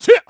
Clause_ice-Vox_Attack1_kr.wav